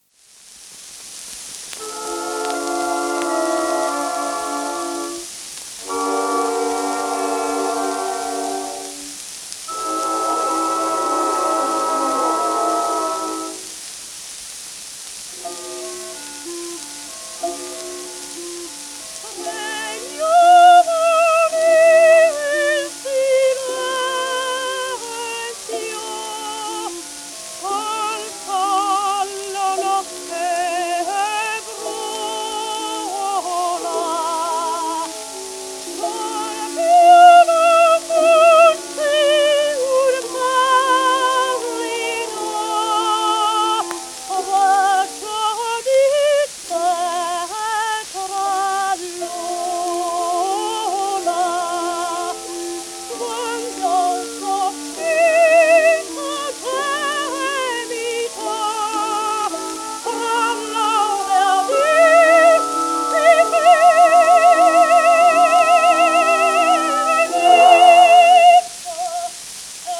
w/オーケストラ
12インチ片面盤
1909年録音